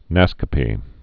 (năskə-pē)